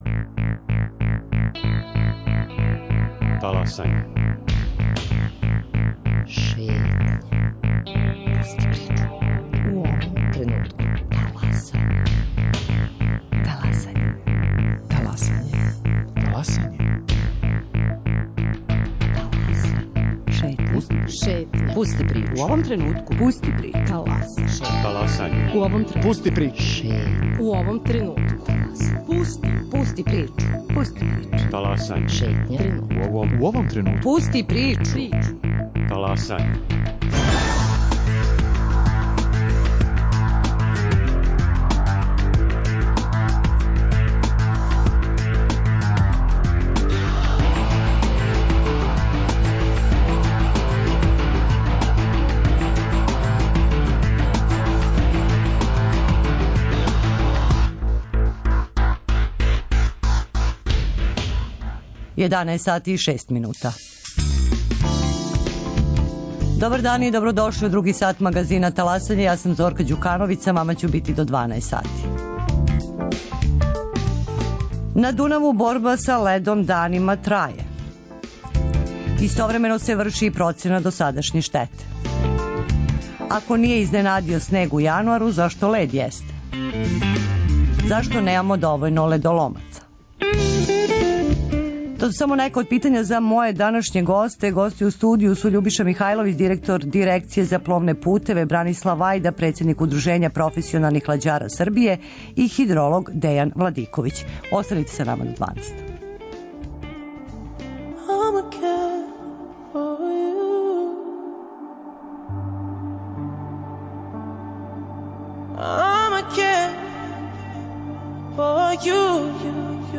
Гости: Љубиша Михајловић, директор Дирекције за пловне путеве